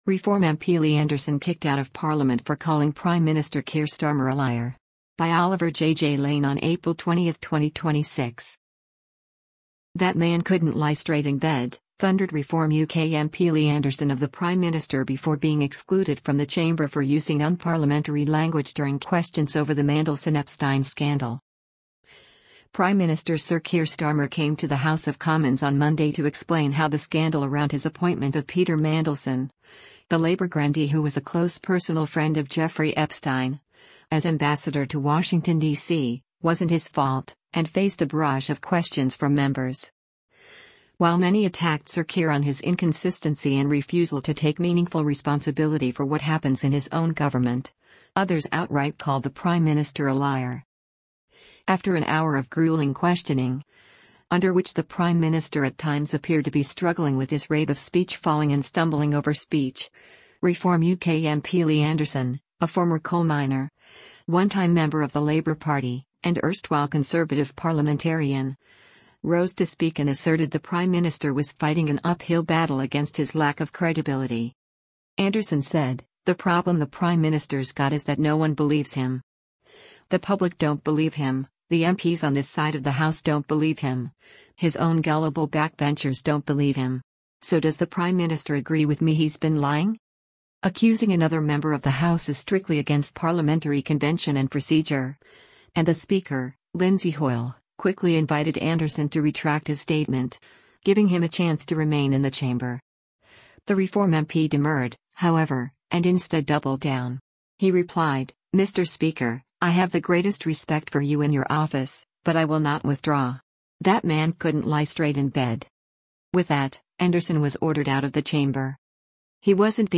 “That man couldn’t lie straight in bed”, thundered Reform UK MP Lee Anderson of the Prime Minister before being excluded from the chamber for using unparliamentary language during questions over the Mandelson-Epstein scandal.
After an hour of gruelling questioning, under which the Prime Minister at times appeared to be struggling with his rate of speech falling and stumbling over speech, Reform UK MP Lee Anderson, a former coal miner, one-time member of the Labour Party, and erstwhile Conservative parliamentarian, rose to speak and asserted the Prime Minister was fighting an uphill battle against his lack of credibility.